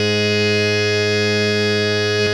52-key04-harm-g#2.wav